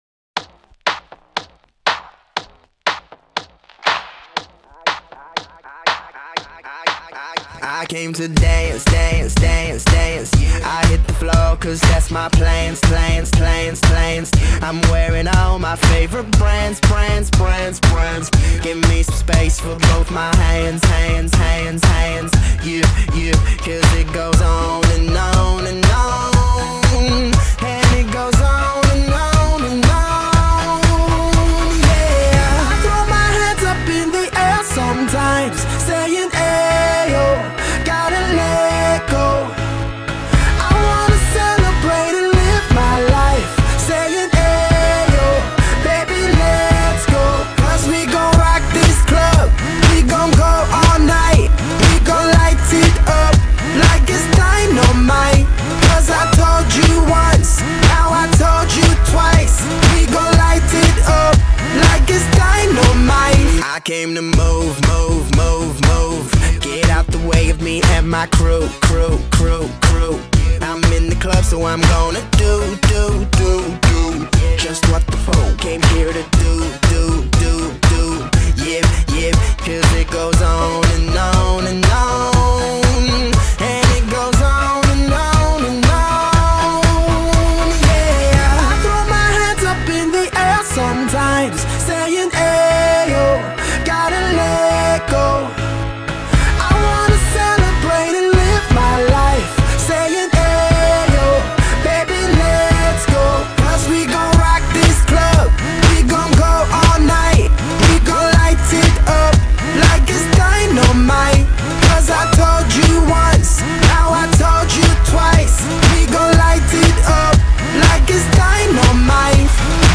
dance-pop anthem
Driven by an upbeat tempo and synth-heavy production